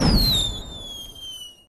firework_whistle_01.ogg